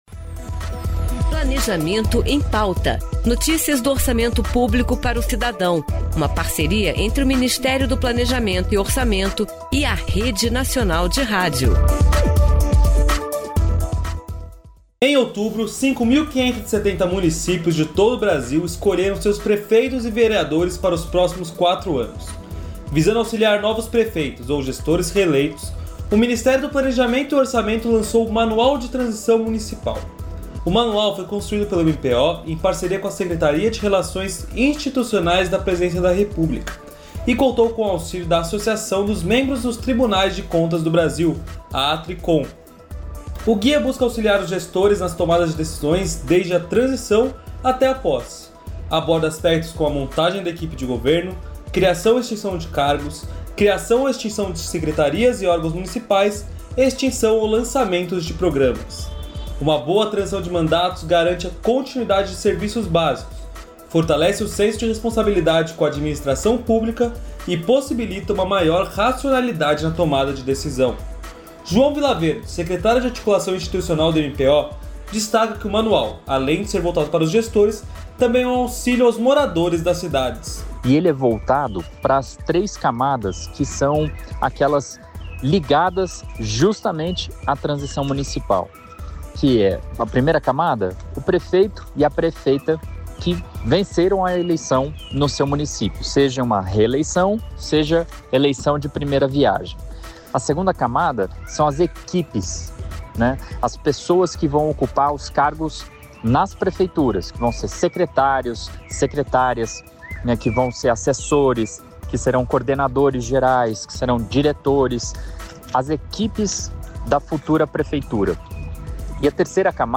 Neste Planejamento em Pauta especial conversamos com vários profissionais do MPO que explicaram o papel da carreira para o Estado brasileiro, as novidades do concurso deste ano e a importância de um perfil diversificado de servidores.